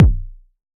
RDM_TapeA_SY1-Kick01.wav